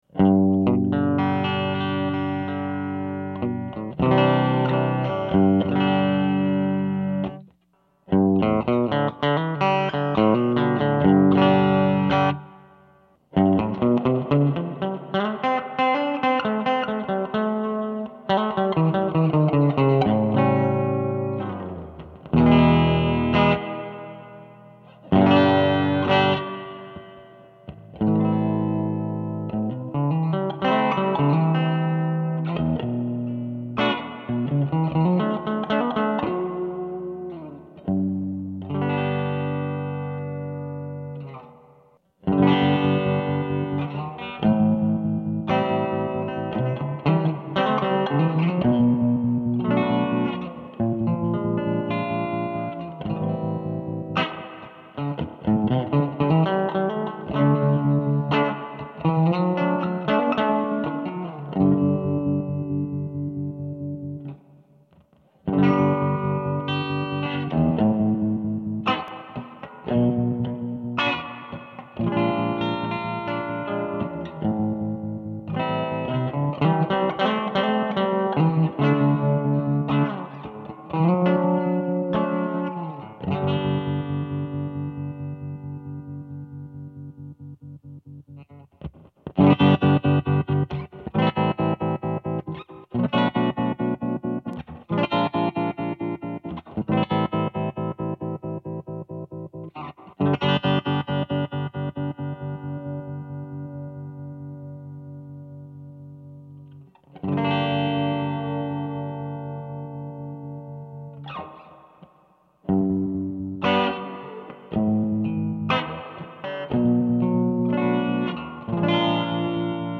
All tube, transformer driven reverb with a tube based tremolo. These are the first photos from the bench and the audio sample captures a taste of the tones this effect produces. The Purple Surfer can boost your clean signal by several dB and offers Dwell, Reverb and Tone controls for the Reverb system and Speed and Depth for the Tremolo system.
These short samples were made with a Tele; sporting Tom Short Country Coils, straight into the Purple Surfer, then into a Swampdonkey Gypsy's Normal channel with a 12AY7 preamp tube...